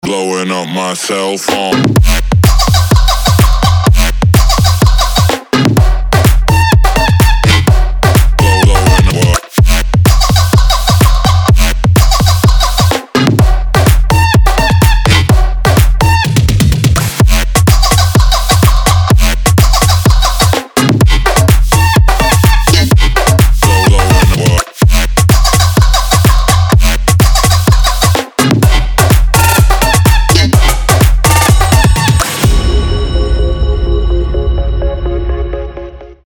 громкие
жесткие
Electronic
EDM
Trap
Bass House
electro house
Любопытное сочетание electro house и trap.